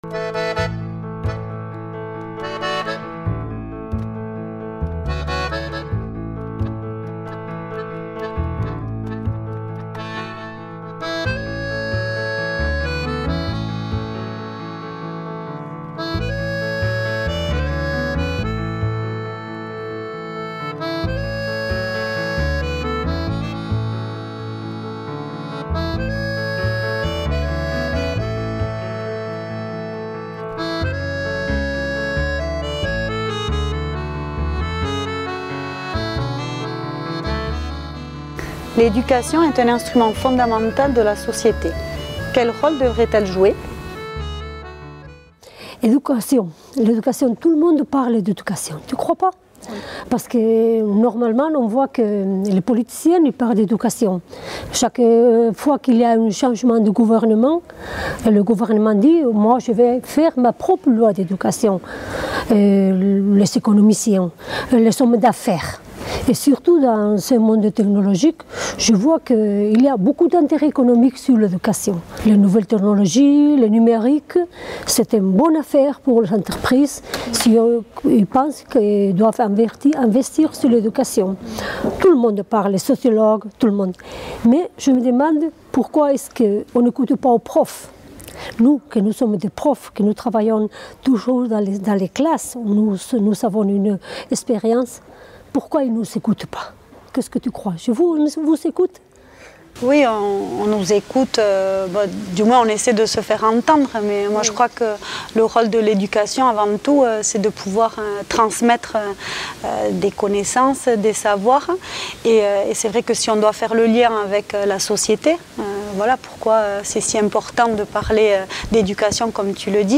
Deux femmes ayant une expérience d'enseignement discutent ce mois-ci du système éducatif basque dans la section audiovisuelle de Solasaldiak.